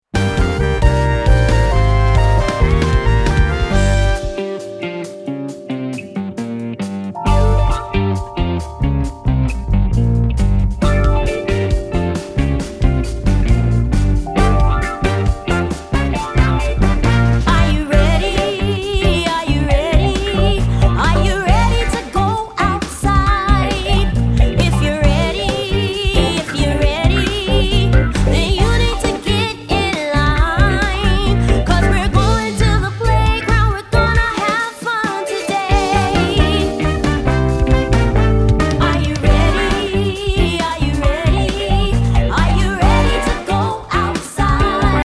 doo-wop and 80s R&B inspired
All tracks except Radio Edits include scripted dialogue.